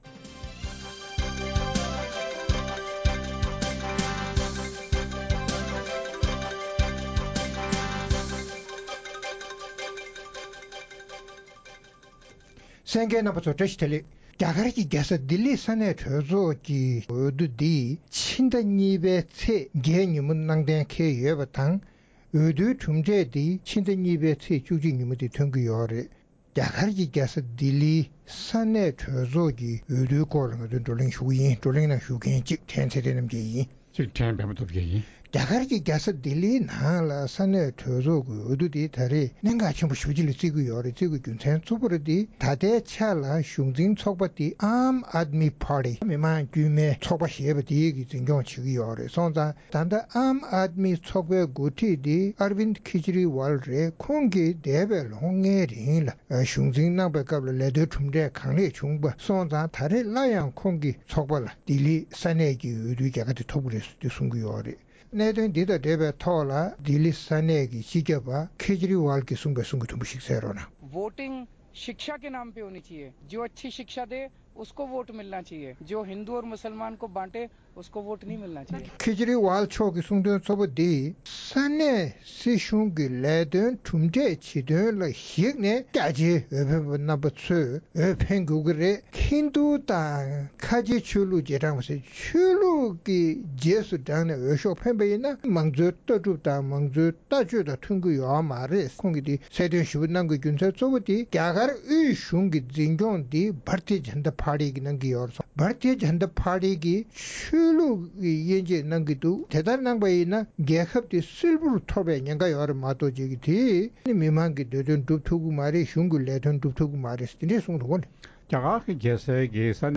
རྩོམ་སྒྲིག་པའི་གླེང་སྟེགས་ཞེས་པའི་ལེ་ཚན་ནང་།རྒྱ་གར་གྱི་རྒྱལ་ས་ལྡི་ལིའི་ས་གནས་འཐུས་མིའི་འོས་བསྡུ་གནང་ལ་ཉེ་བའི་སྐབས་དང་བསྟུན་སྲིད་དོན་ཚོགས་པ་སོ་སོའི་འོས་བསྡུའི་དྲིལ་བསྒྲགས་ཀྱི་རྩ་དོན་ལ་སོགས་པའི་གནད་དོན་སྐོར་རྩོམ་སྒྲིག་འགན་འཛིན་རྣམ་པས་བགྲོ་གླེང་གནང་བར་གསན་རོགས་ཞུ།།